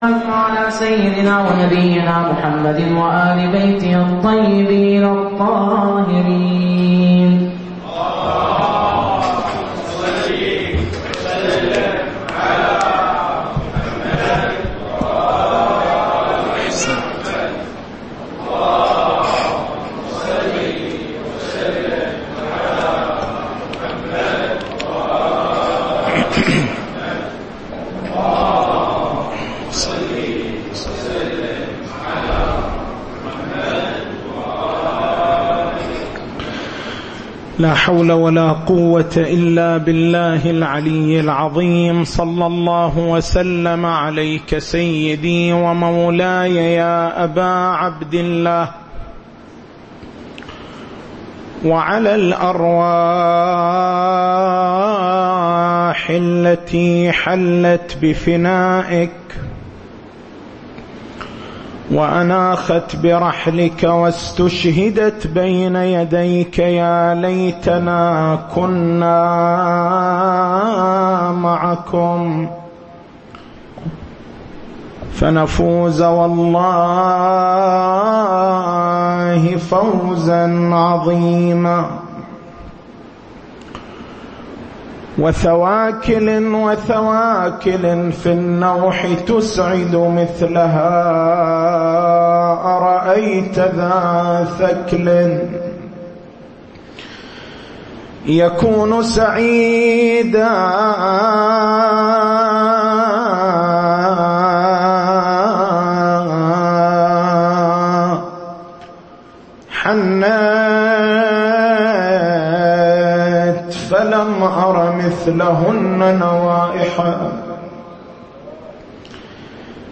تاريخ المحاضرة: 28/09/1436 نقاط البحث: بيان المقصود من الأعراف معنى الأعراف مواصفات الأعراف بيان هوية رجال الأعراف التسجيل الصوتي: اليوتيوب: شبكة الضياء > مكتبة المحاضرات > شهر رمضان المبارك > 1436